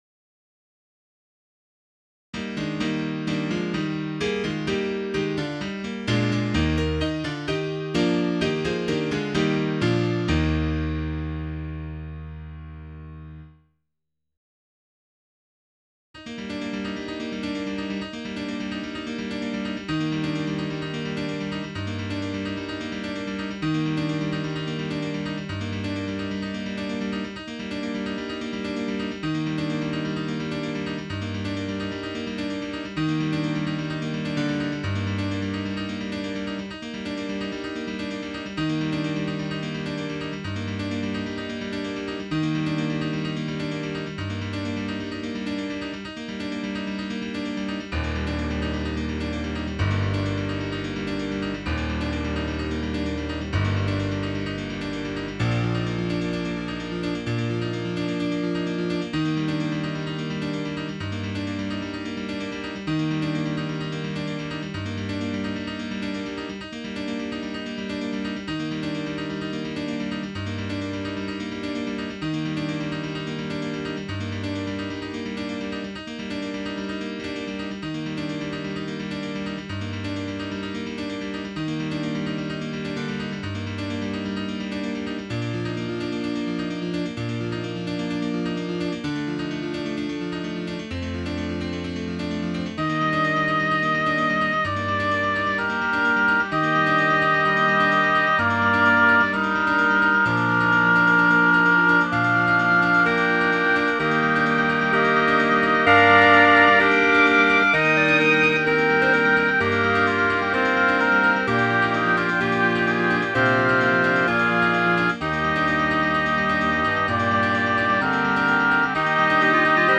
Tags: Piano, Clarinet, Woodwinds, Percussion